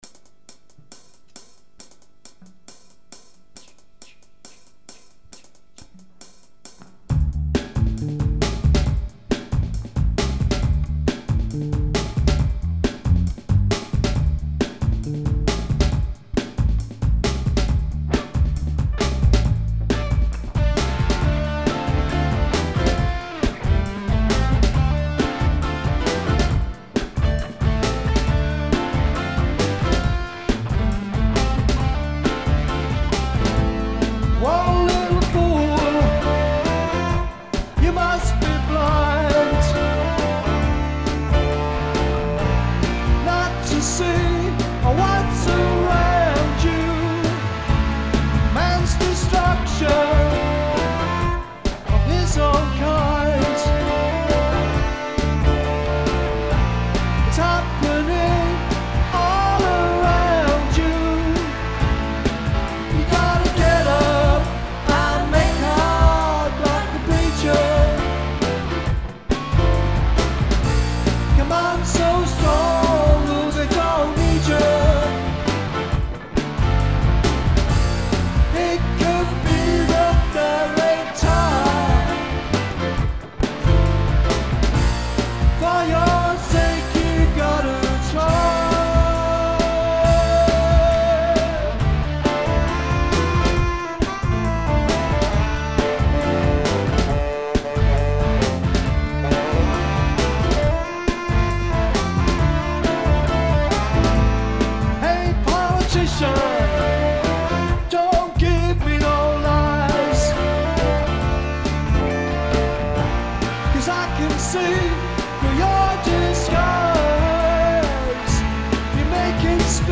(live)
keyboards and lead vocals
guitar and bass
sax and harmony vocals